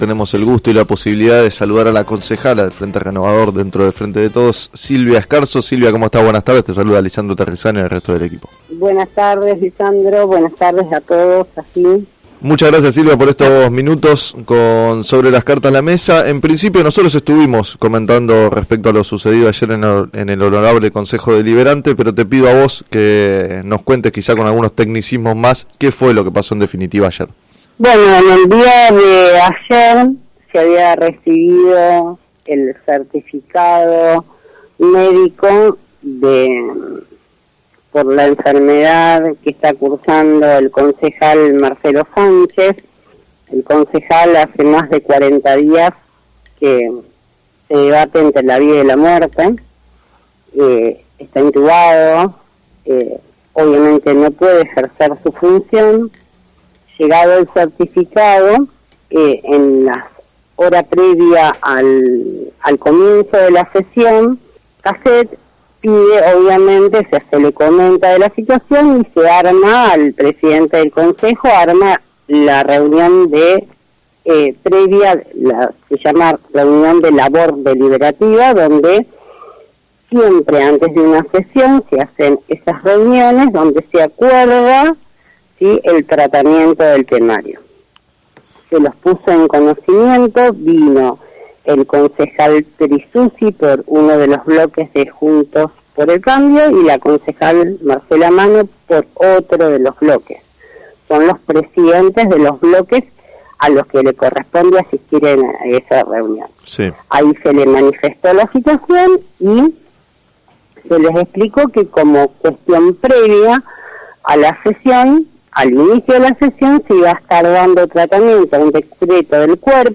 Silvia Scarzo, concejal del oficialismo, en declaraciones al programa “Sobre las cartas la mesa” de FM Líder 97.7, explicó que tomaron esta decisión ante la situación planteada el martes por el intento opositor de dilatar el reemplazo para sostener una mayoría circunstancial.